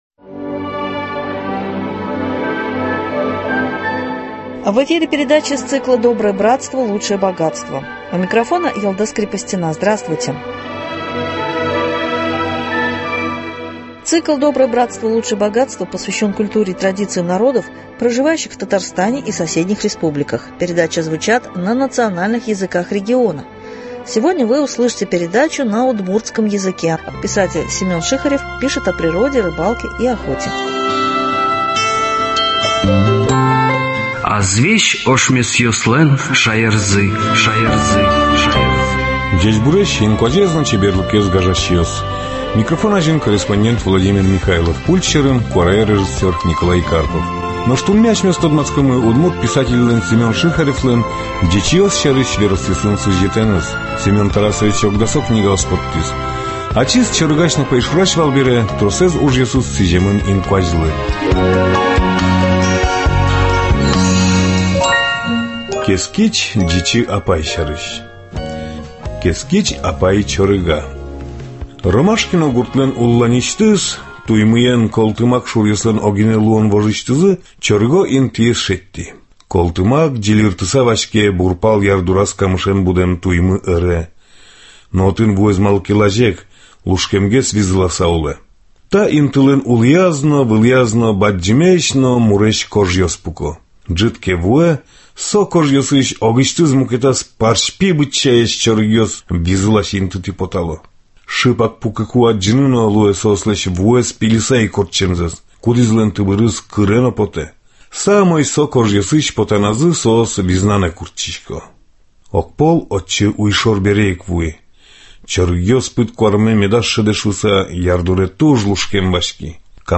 Встречи с деятелями культуры и искусства Удмуртии ( на удмуртском языке, из фондов радио).